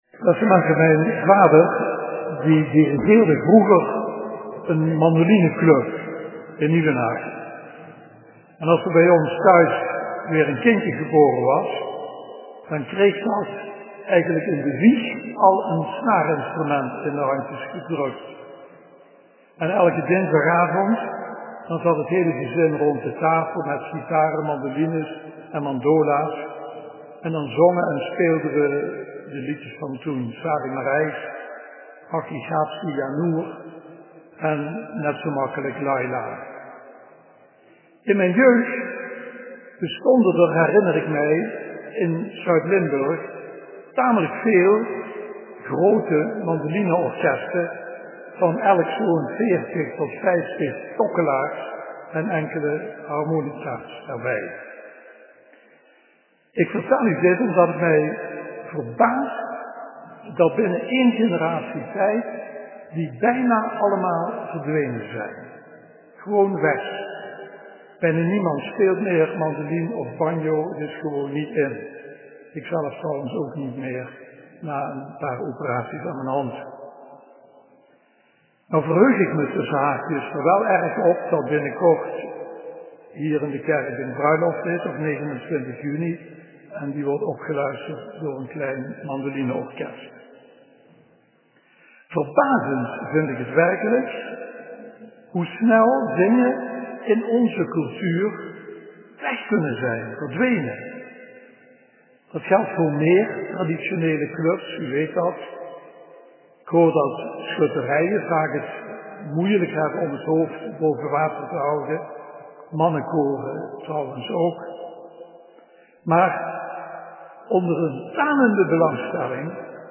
2019 preek pasen 6.mp3